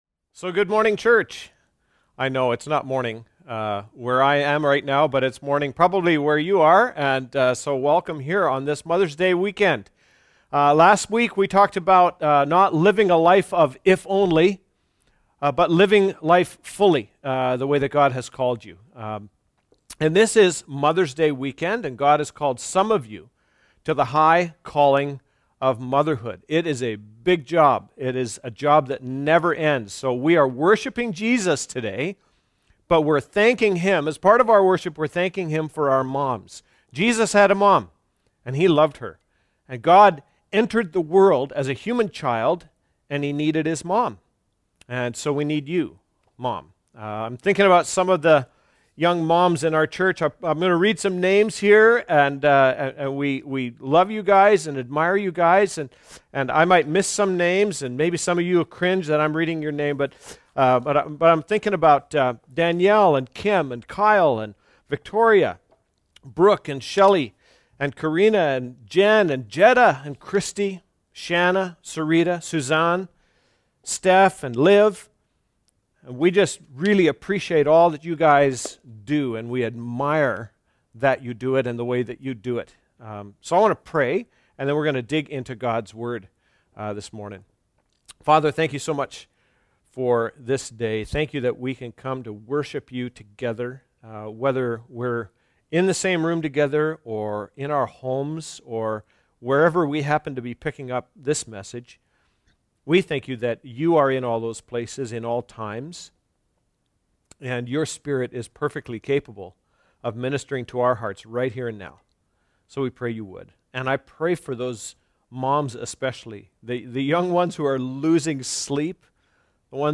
May10-Sermon.mp3